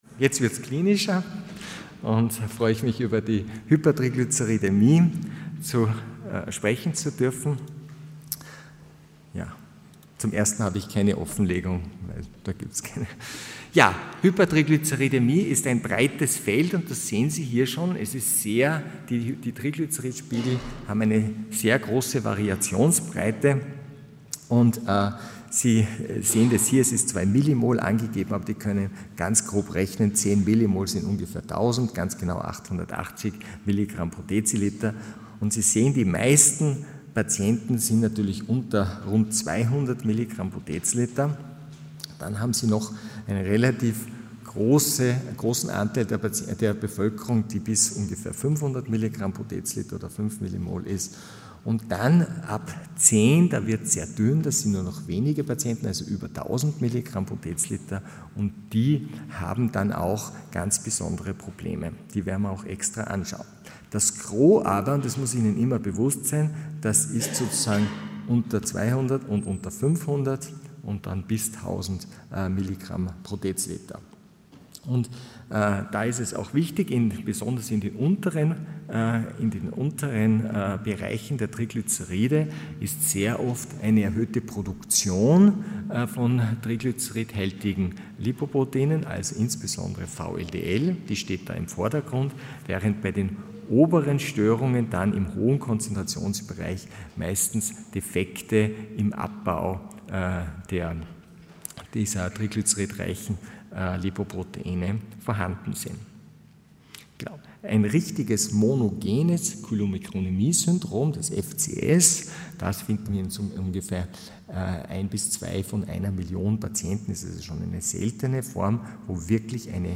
Sie haben den Vortrag noch nicht angesehen oder den Test negativ beendet.
Hybridveranstaltung | Lange Nacht der Lipide in Kooperation mit der Cholesterinallianz Loading the player... 0